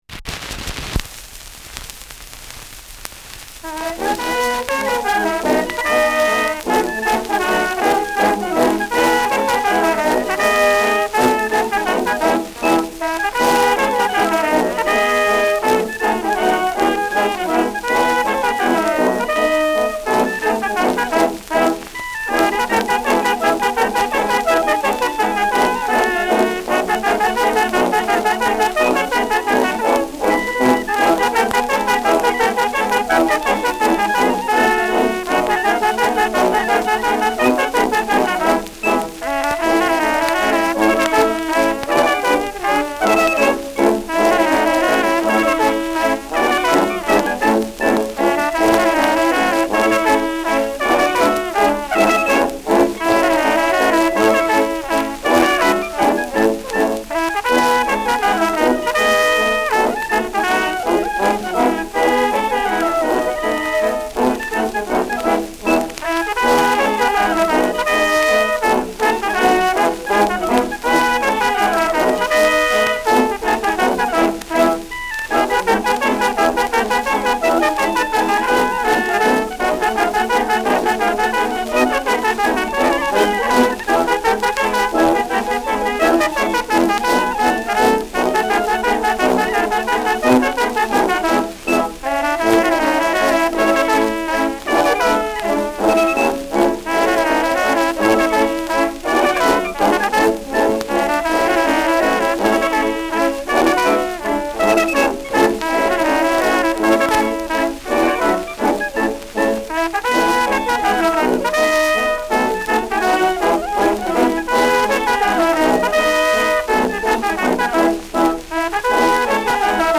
Schellackplatte
präsentes Rauschen
[Ansbach] (Aufnahmeort)